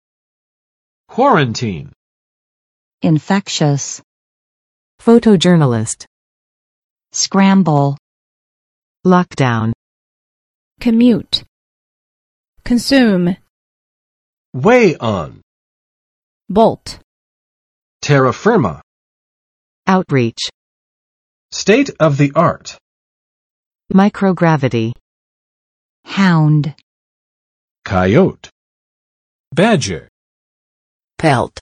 [ˋkwɔrən͵tin] v. 使隔离；使受检疫